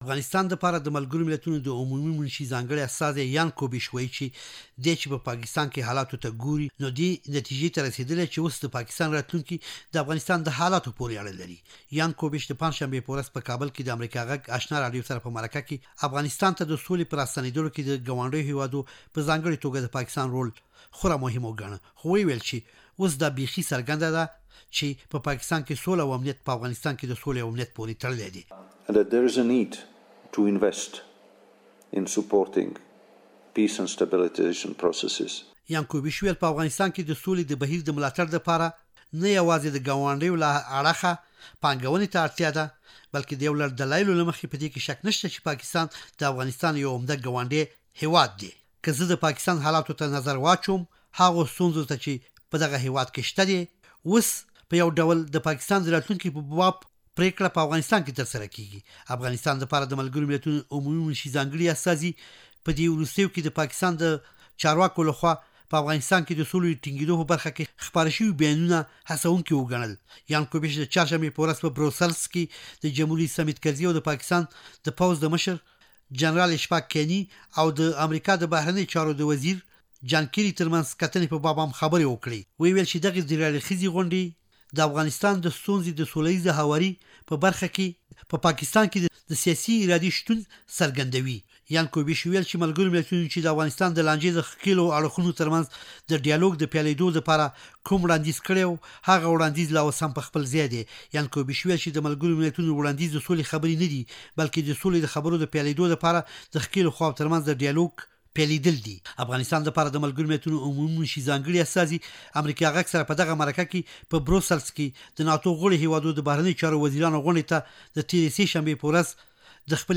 د یان کوبیش سره د آشنا راډیو د ځانګړې مرکې راپور